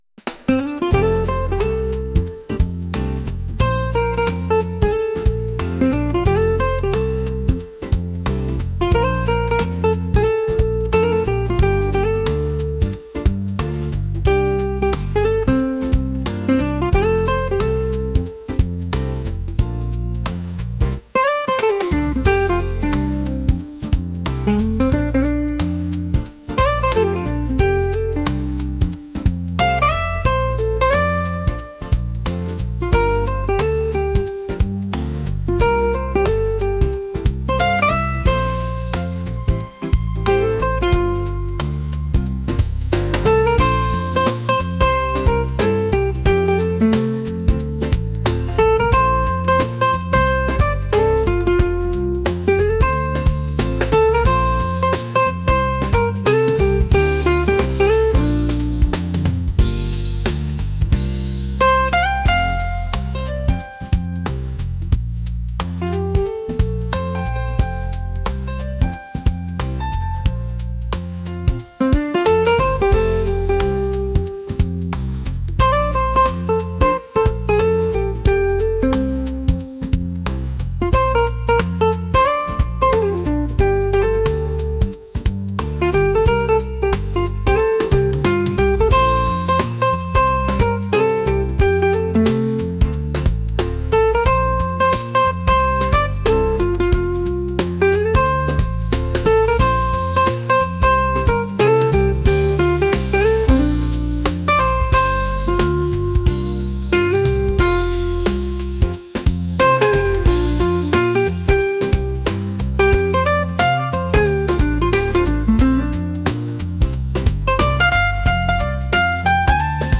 jazz | smooth